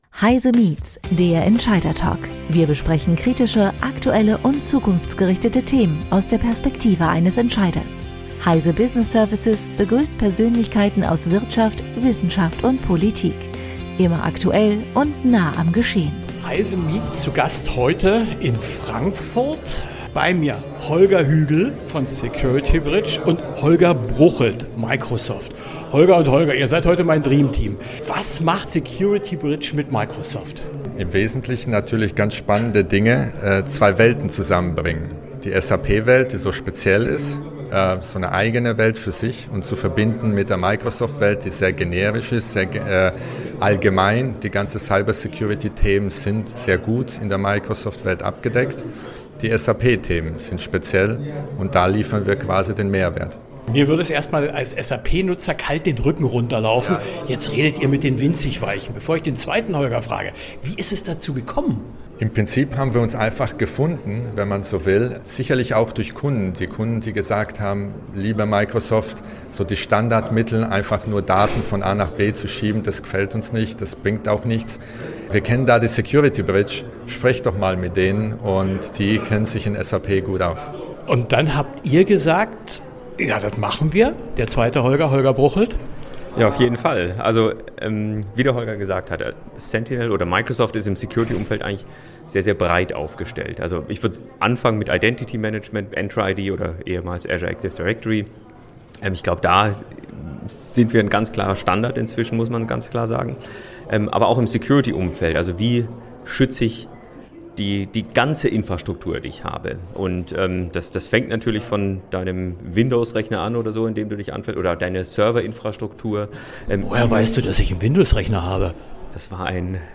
Wir besprechen kritische, aktuelle und zukunftsgerichtete Themen aus der Perspektive eines Entscheiders. Wir begrüßen Persönlichkeiten aus Wirtschaft, Wissenschaft und Politik.